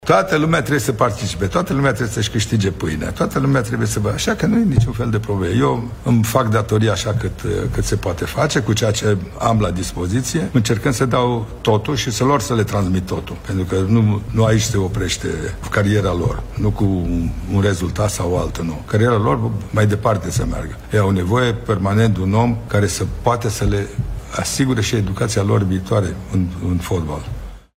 „Eu îmi voi face datoria cât se poate” – a spus Mircea Lucescu, întrebat de jurnaliști de o eventuală demisie.